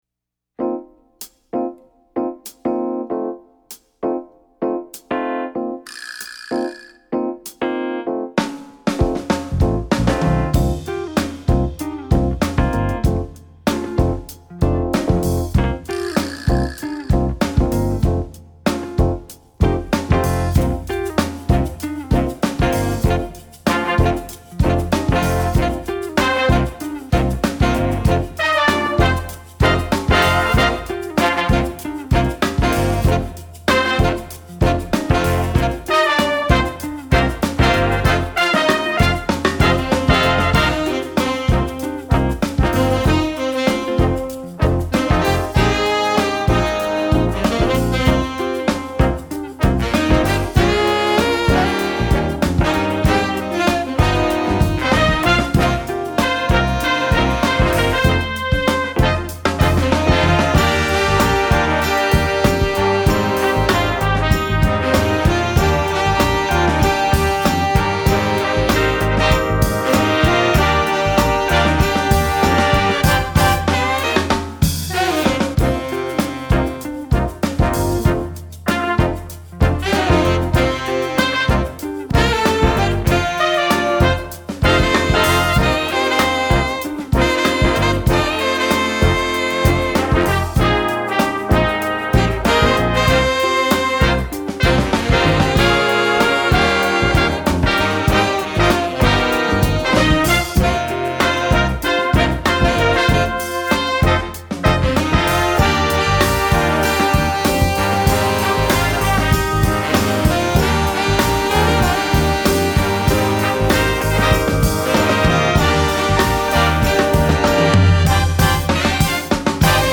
MUJ 輸入ジャズバンド（スコア＆パート）
Eb Alto Saxophone 1
Eb Baritone Saxophone
Trumpet 1
Trombone 1
Guitar
Piano
Bass
Drums
Aux Percussion